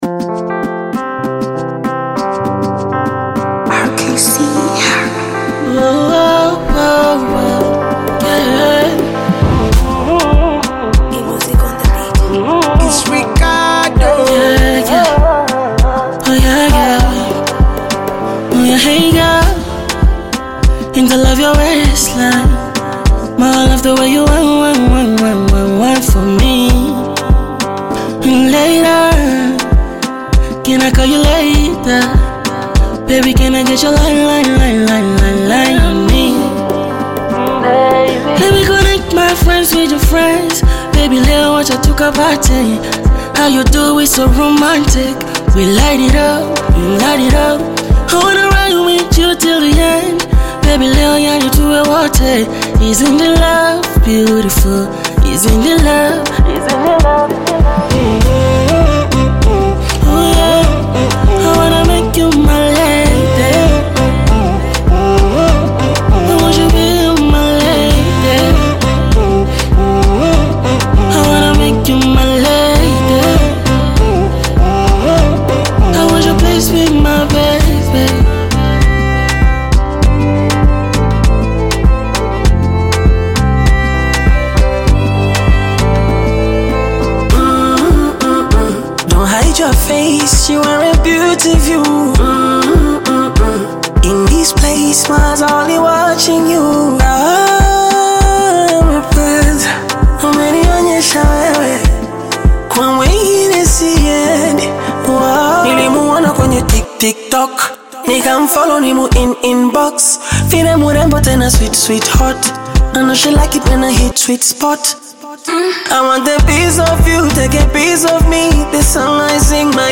creating a song that radiates romance and charm.